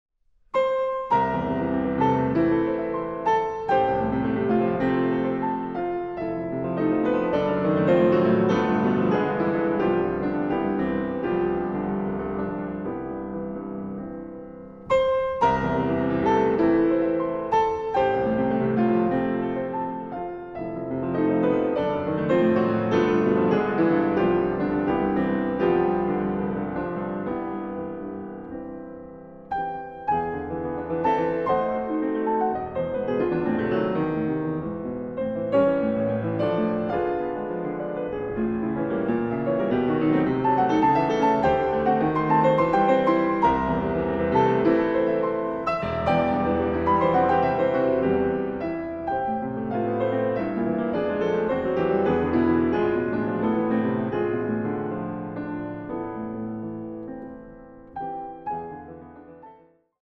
Piano
Intermezzo. Allegro non assai, ma molto appassionato